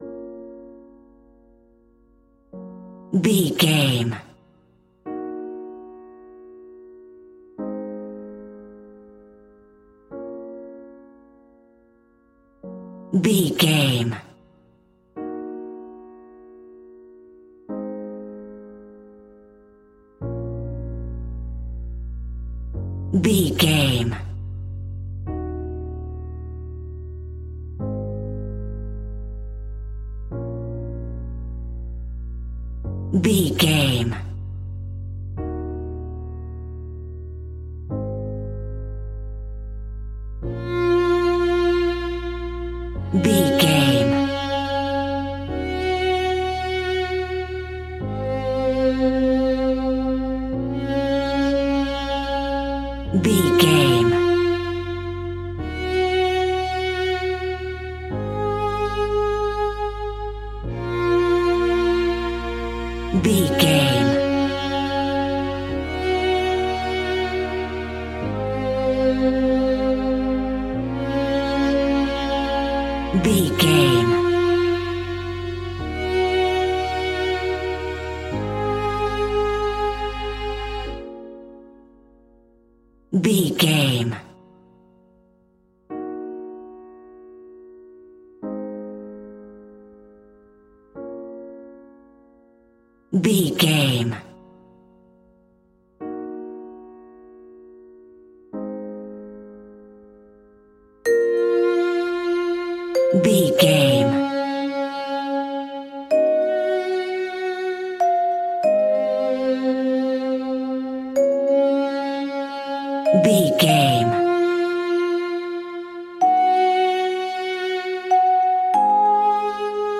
royalty free music
Aeolian/Minor
B♭
dreamy
ethereal
peaceful
melancholy
hopeful
piano
violin
cello
electronic
synths